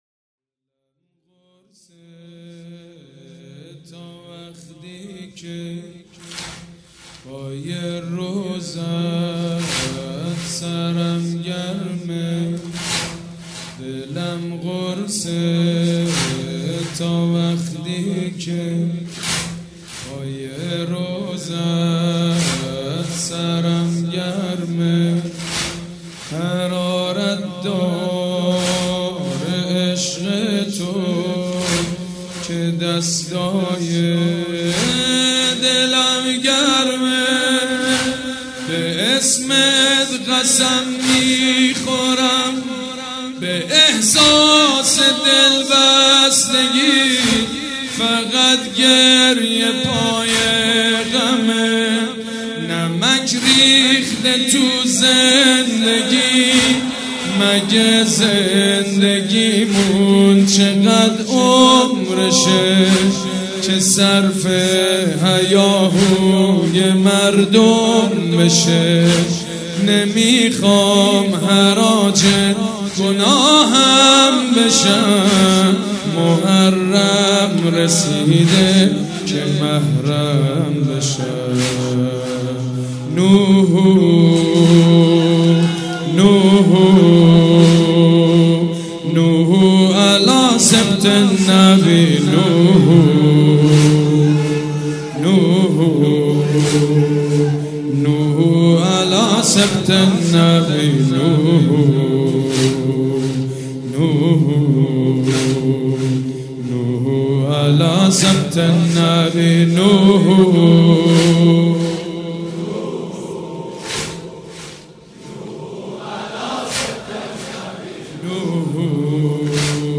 شب پنجم محرم الحرام‌ پنجشنبه ۱5 مهرماه ۱۳۹۵ هيئت ريحانة الحسين(س)
سبک اثــر واحد مداح حاج سید مجید بنی فاطمه
مراسم عزاداری شب پنجم